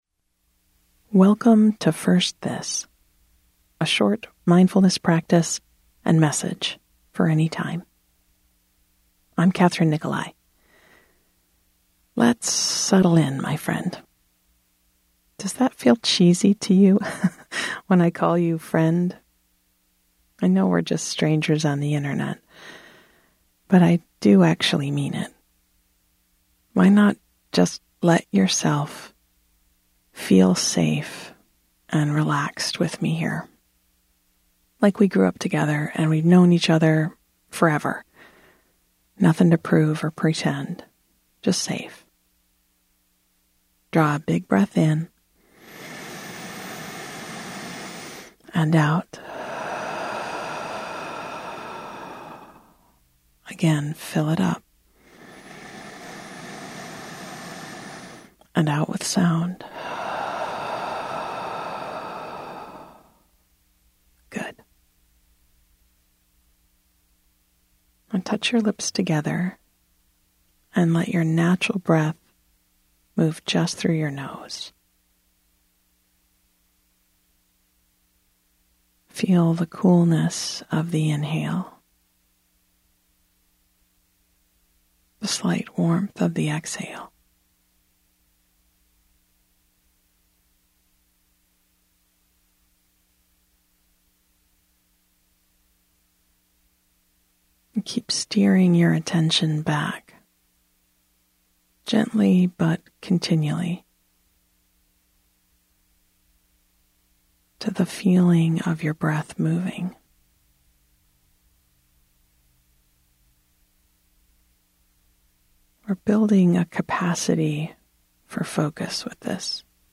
Take ten minutes to settle your mind, observe your breath and be gently lead through a meditation practice.